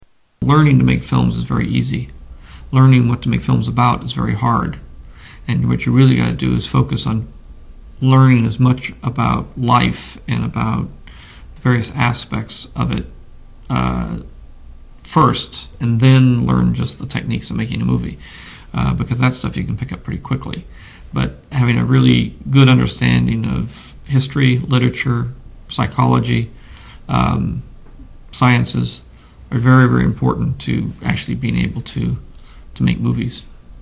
Tags: Media George Lucas audio Interviews George Lucas Star Wars Storywriter